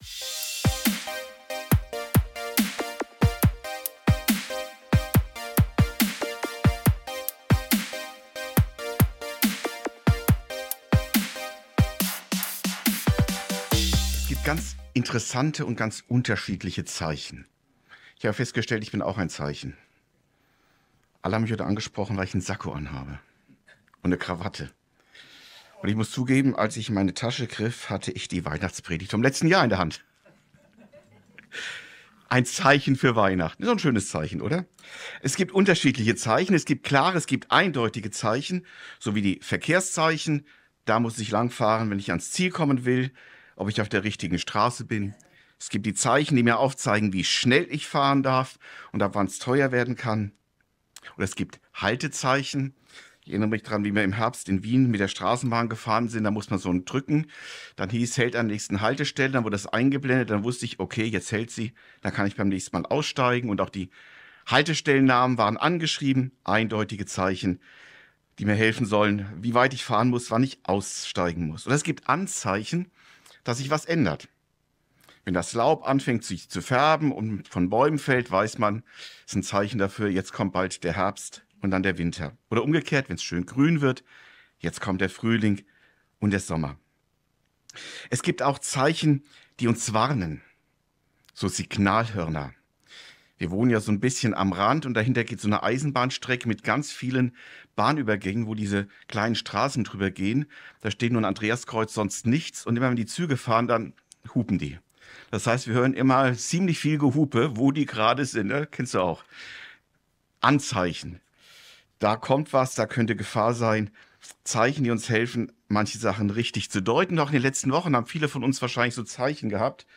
Das Windelzeichen ~ Predigten u. Andachten (Live und Studioaufnahmen ERF) Podcast
Ein seltsames Erkennungszeichen bekommen die Hirten da genannt: Ein Baby, dass in Windeln gewickelt ist. Was ist das für ein Zeichen - und welche Bedeutung haben die anderen Zeichen, wenn Gott etwas außergewöhnliches tut? Weihnachtspredigt